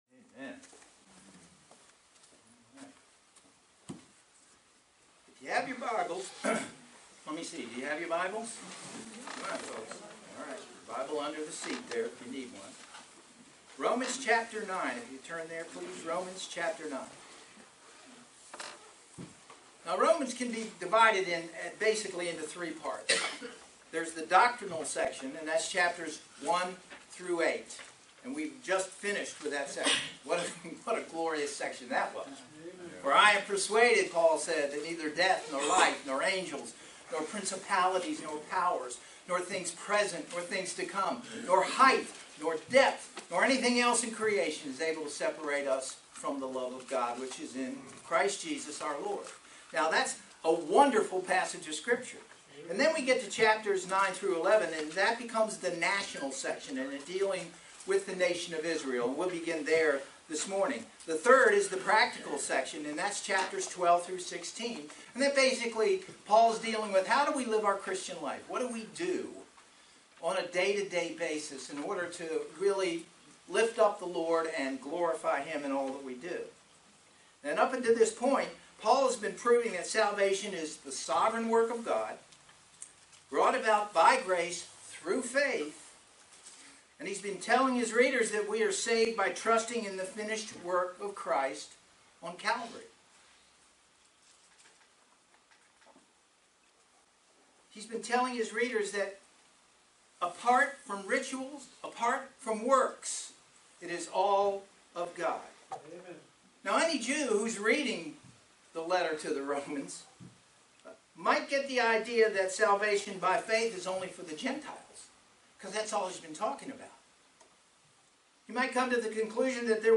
Passage: Romans 9:1-5 Service Type: Sunday Morning Worship